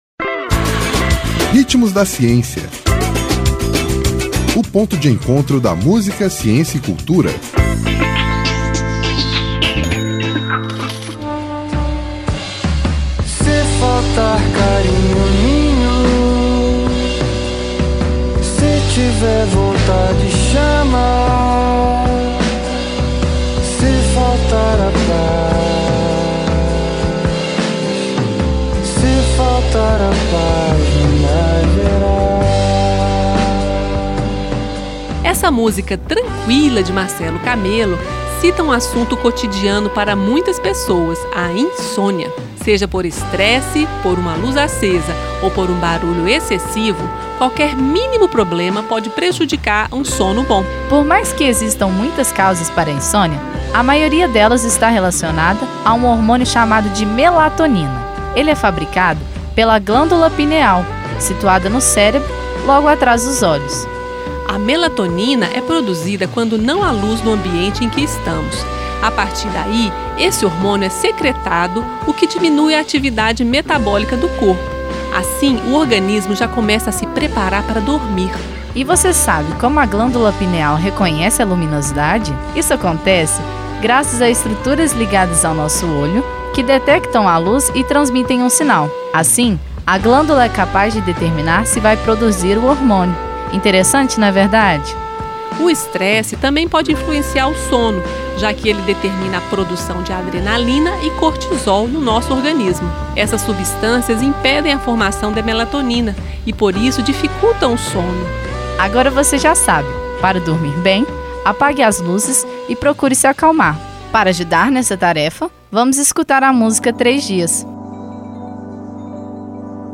Essa música tranquila de Marcelo Camelo cita um assunto cotidiano para muitas pessoas: a insônia! Seja por estresse, por uma luz acesa, ou por barulho excessivo, qualquer mínimo problema, pode prejudicar um sono bom…Ouça todo o programa: